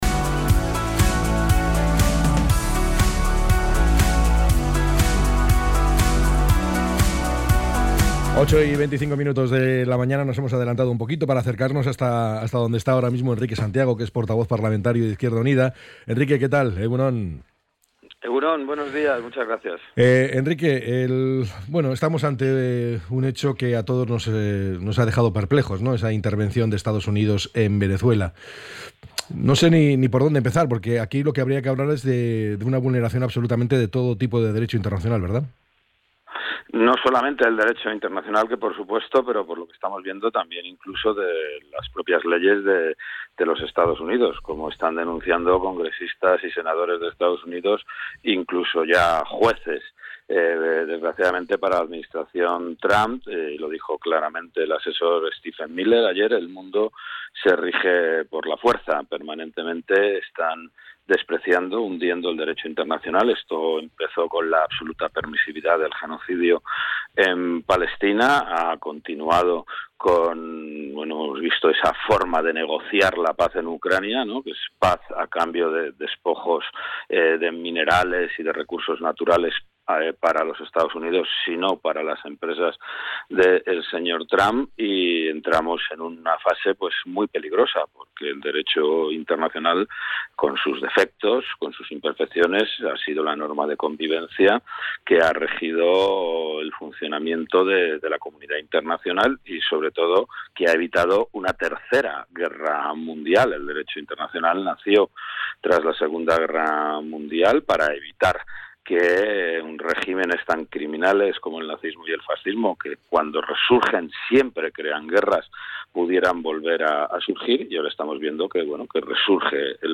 ENTREV.-ENRIQUE-SANTIAGO.mp3